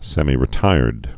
(sĕmē-rĭ-tīrd, sĕmī-)